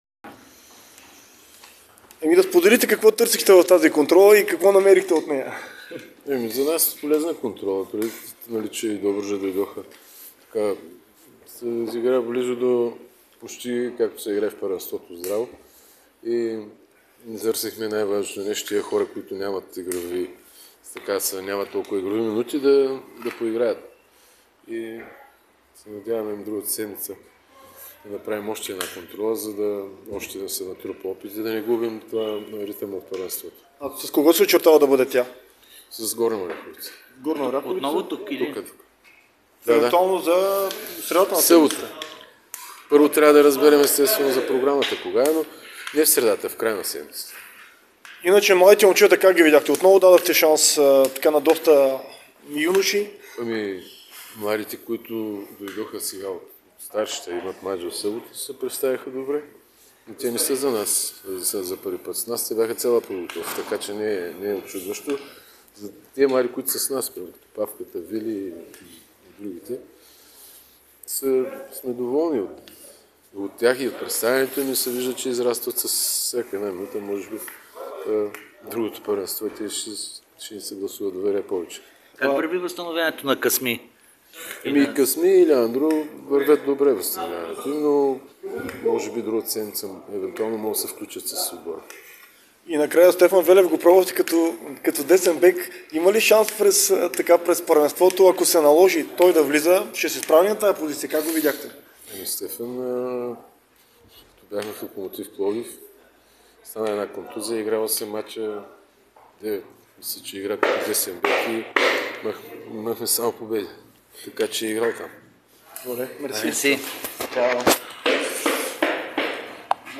Треньорът на Черно море Илиан Илиев сподели впечатленията си след победата над Добруджа с 2:1 в контролна среща, играна на "Тича".